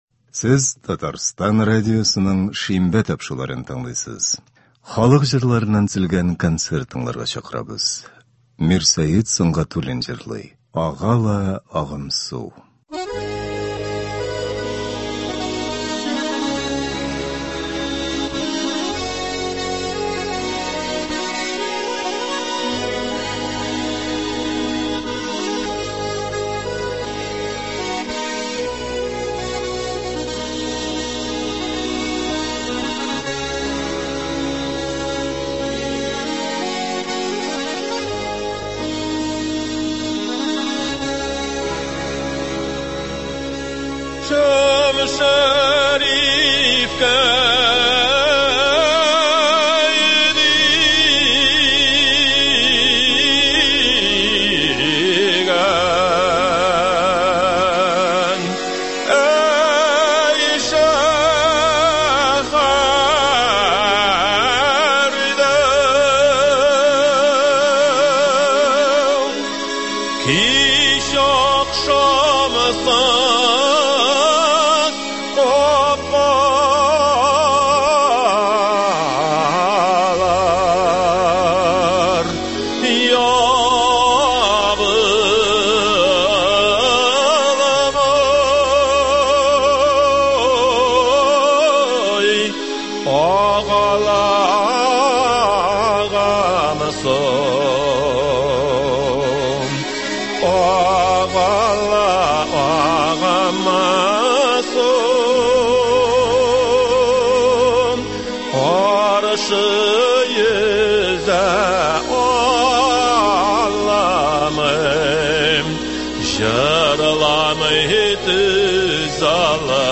Татар халык көйләре (05.11.22)
Бүген без сезнең игътибарга радио фондында сакланган җырлардан төзелгән концерт тыңларга тәкъдим итәбез.